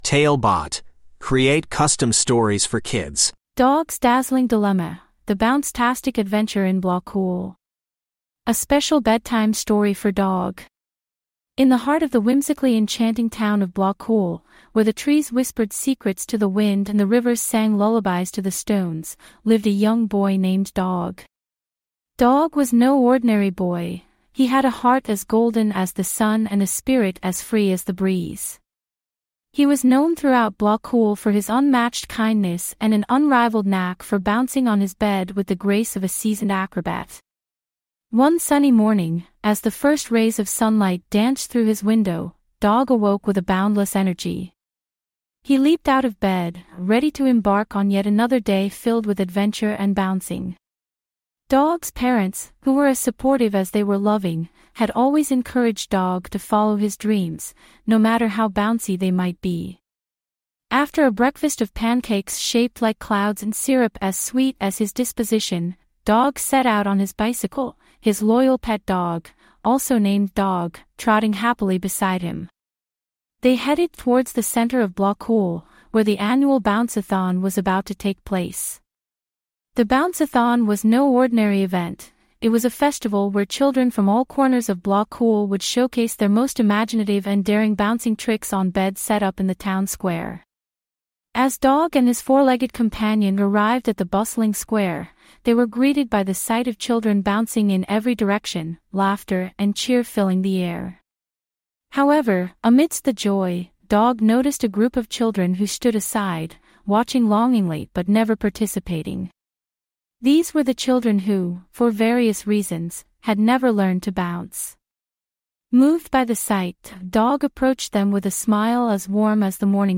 TaleBot AI Storyteller
Write some basic info about the story, and get it written and narrated in under 5 minutes!